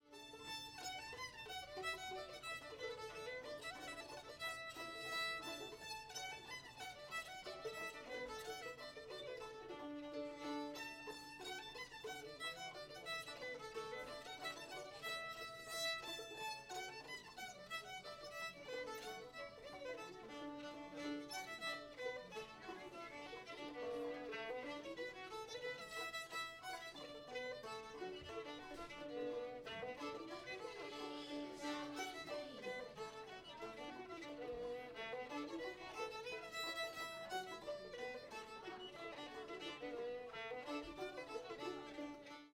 belle of lexington [D]